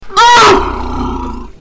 c_elep_hit1.wav